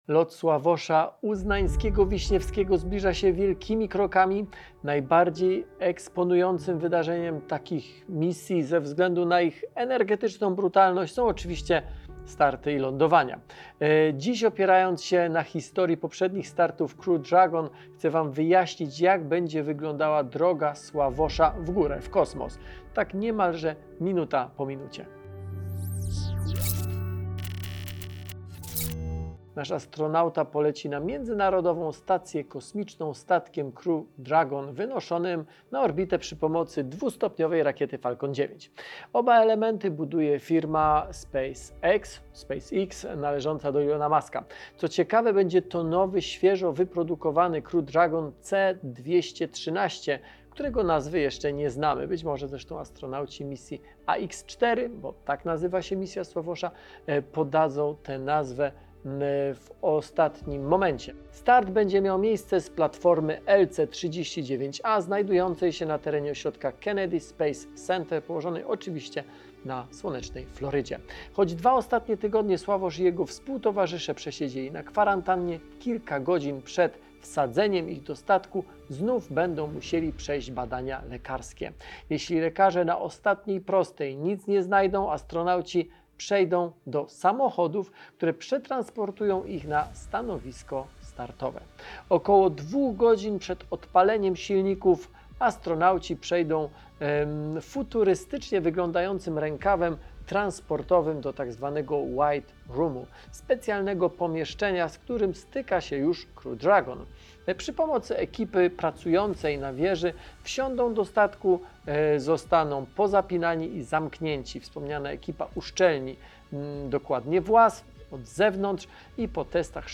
W tym odcinku zabieram Was do zakładu przetwarzania elektrośmieci i krok po kroku pokazuję, jak odzyskujemy cenne surowce.